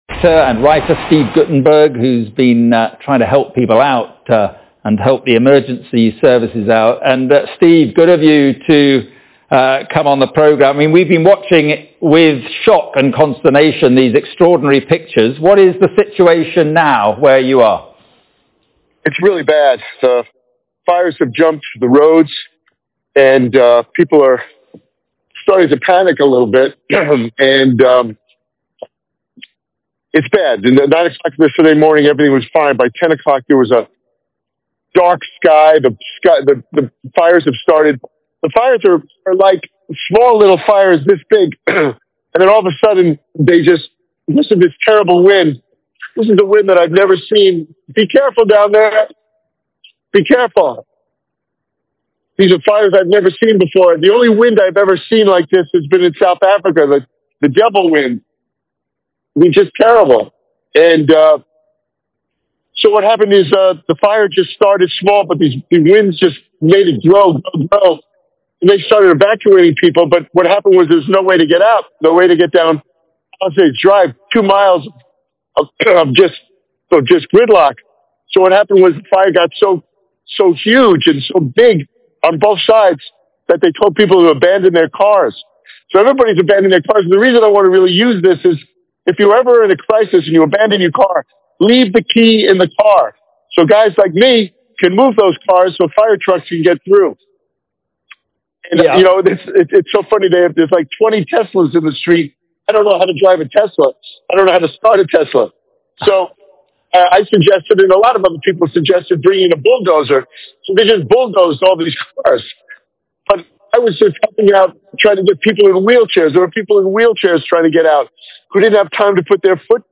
Steve Guttenberg, an American actor, producer and director (who is also an author and businessman), granted a telling interview to Sky News (a British news channel) about the California fires currently raging in parts of the US state, with the homes of various Hollywood celebrities (and other properties) reduced to ashes.
California-wildfires-Actor-Steve-Guttenberg-calls-on-National-Guard-to-be-brought-in.mp3